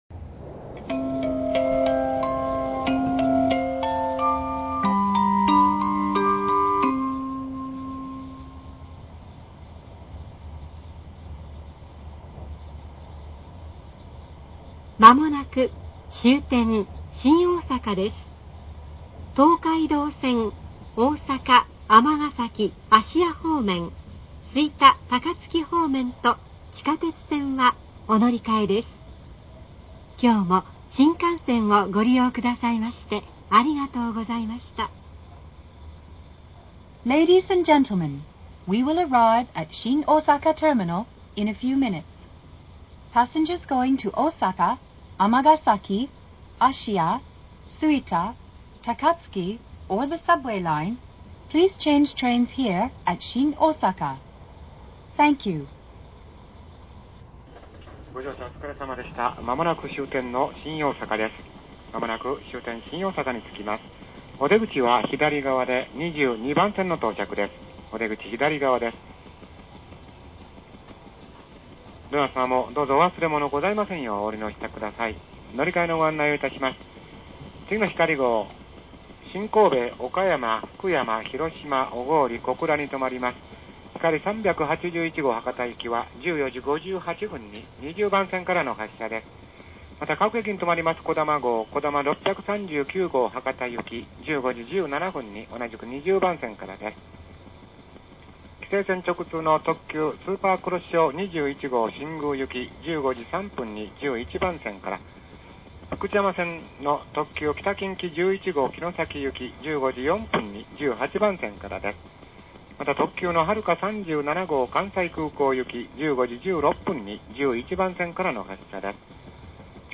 JR東海　車内放送
新大阪到着前   のぞみチャイム